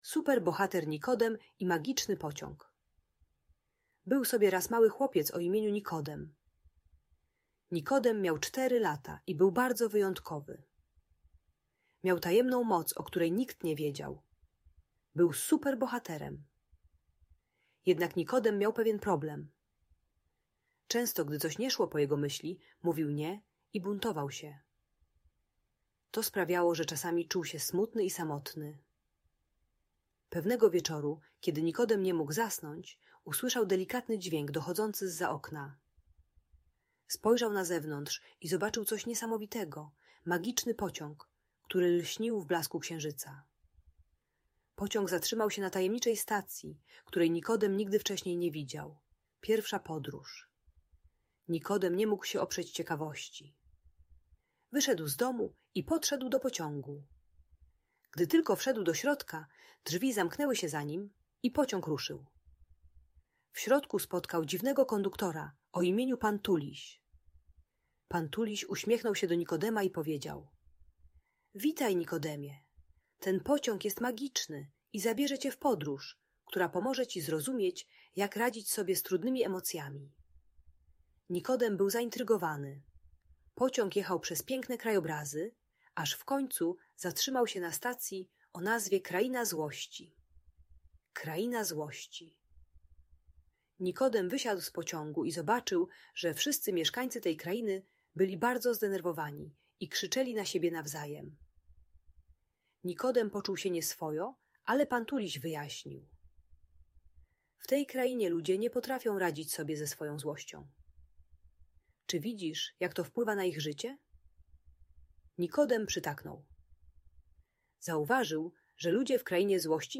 Super Bohater Nikodem i Magiczny Pociąg - Audiobajka